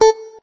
note_beepey_6.ogg